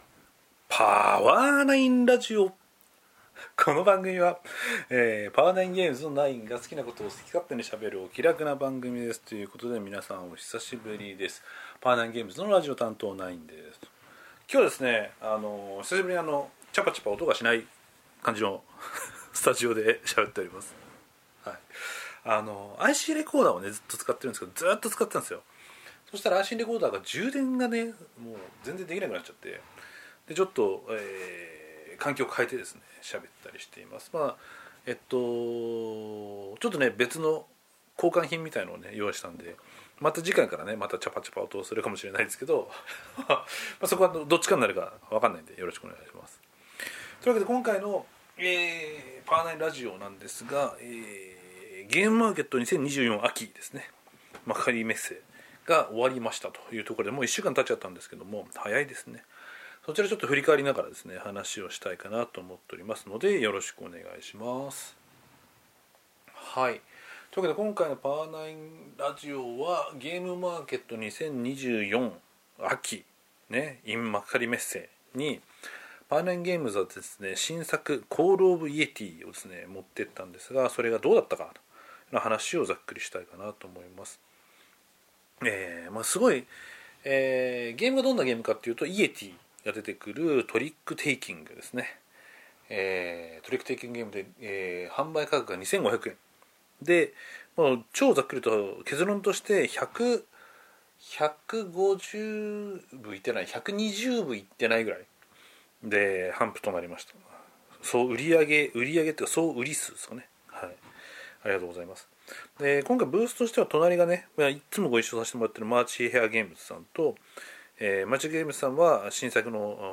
今回はゲムマでの反省や、感想、販売の結果報告などをダーっと話しております。 （収録スタジオがいつもと違います（健全）） このブラウザでは再生できません。